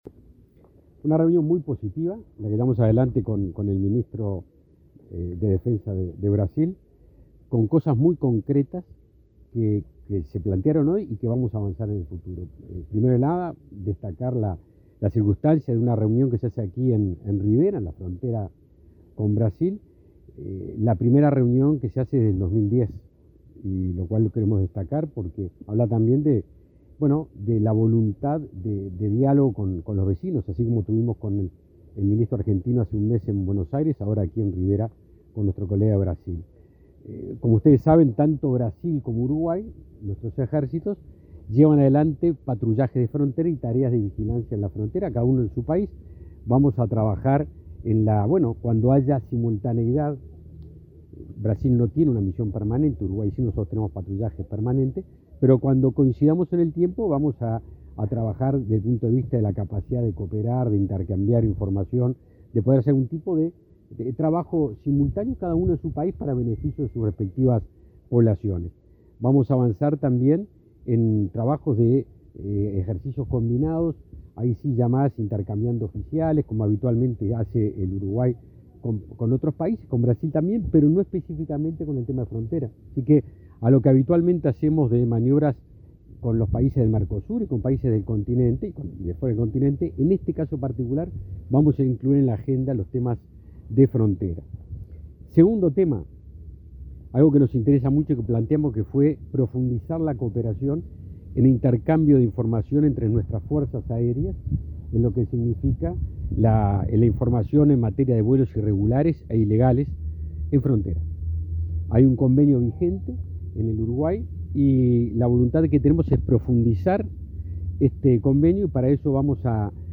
Declaraciones a la prensa del ministro de Defensa, Javier García